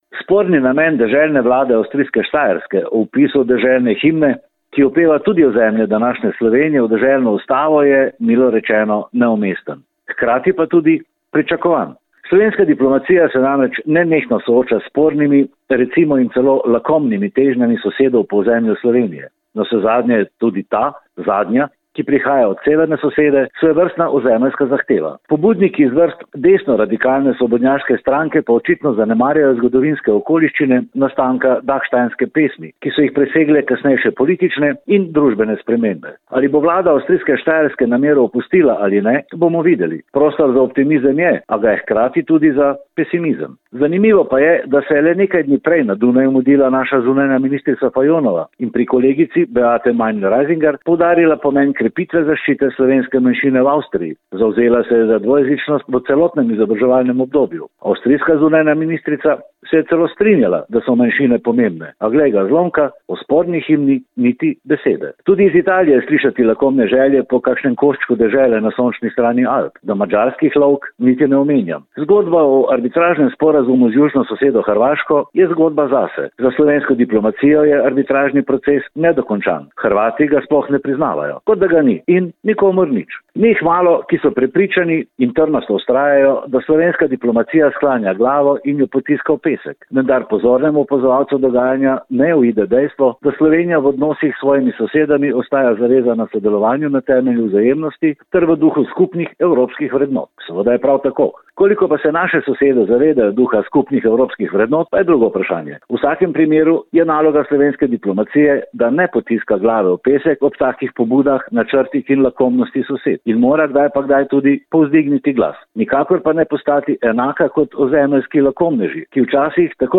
Komentar je stališče avtorja in ni nujno stališče uredništva.